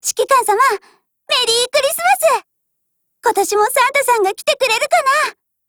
贡献 ） 协议：Copyright，其他分类： 分类:少女前线:MP5 、 分类:语音 您不可以覆盖此文件。